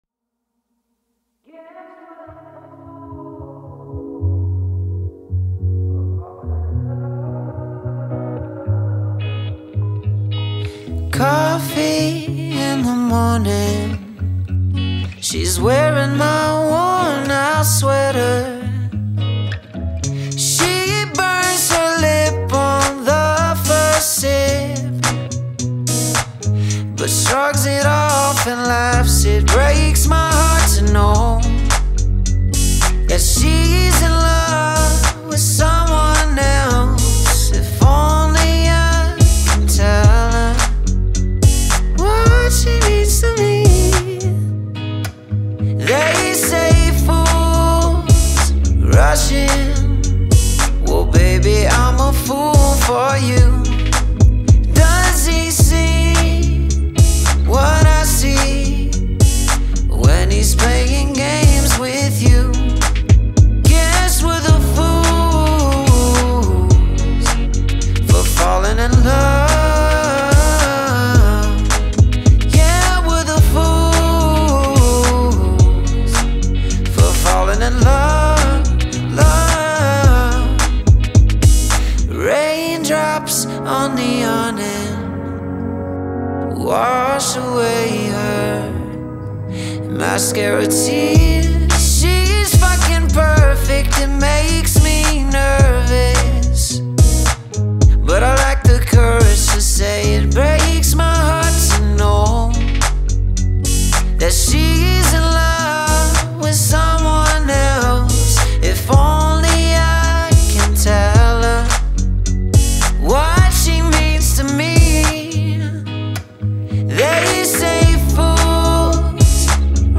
С помощью мелодичной структуры и сильных вокальных партий